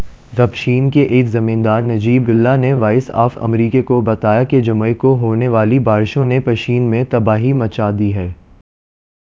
deepfake_detection_dataset_urdu / Spoofed_TTS /Speaker_14 /263.wav